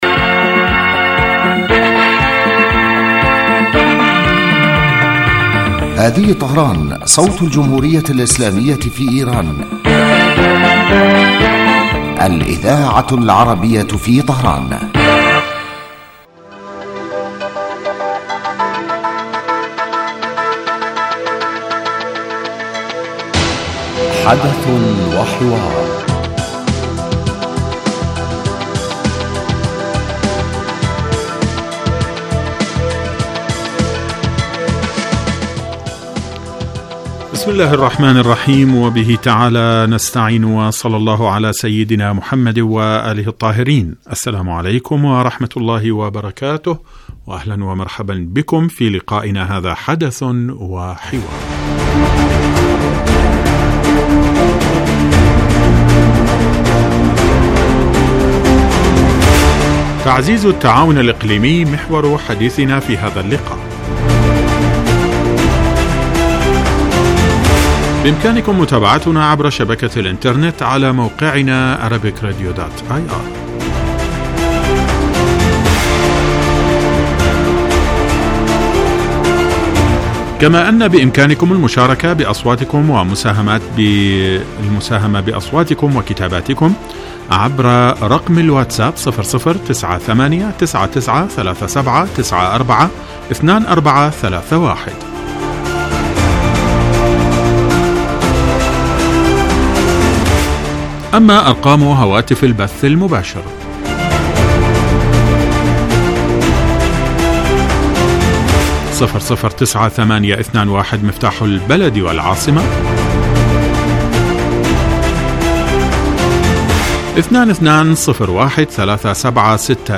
يبدأ البرنامج بمقدمة يتناول فيها المقدم الموضوع ثم يطرحه للنقاش من خلال تساؤلات يوجهها للخبير السياسي الضيف في الاستوديو.
ثم يتم تلقي مداخلات من المستمعين هاتفيا حول الرؤى التي يطرحها ضيف الاستوديو وخبير آخر يتم استقباله عبر الهاتف ويتناول الموضوع بصورة تحليلية.